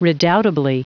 Prononciation du mot redoubtably en anglais (fichier audio)
redoubtably.wav